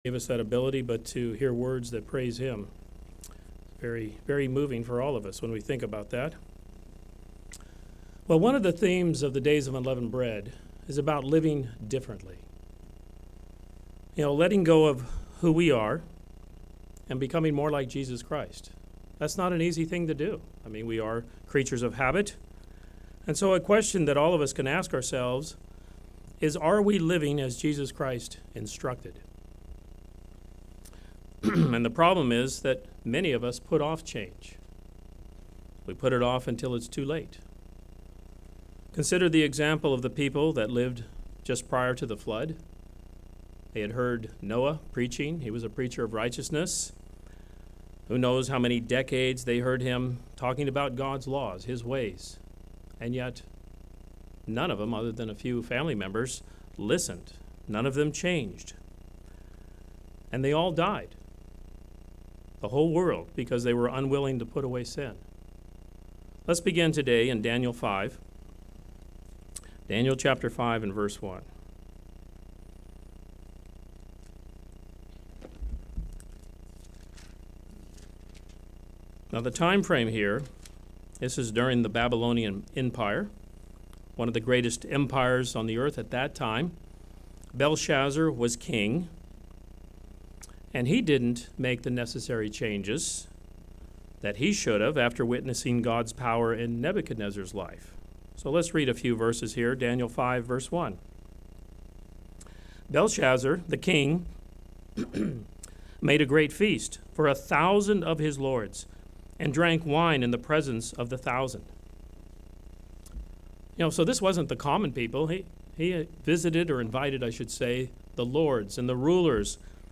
Sermons
Given in St. Petersburg, FL